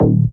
Подскажите как накрутить такой бас.